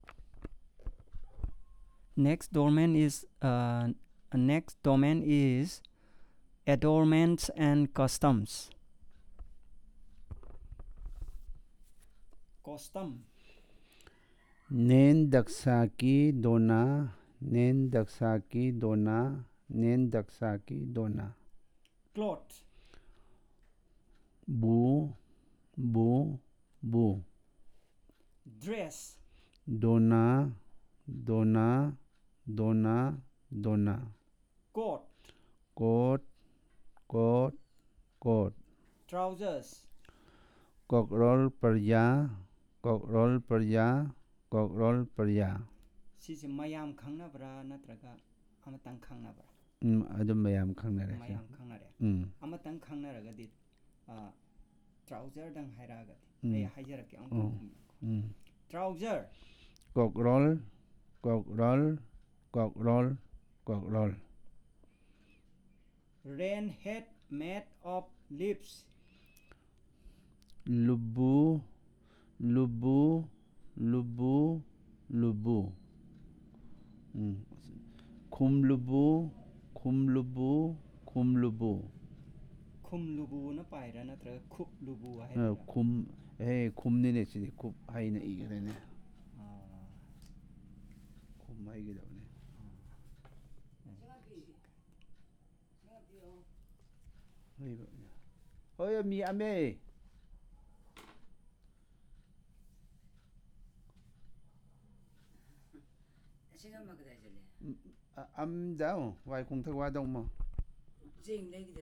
Elicitation of words about adornments and costumes, part 1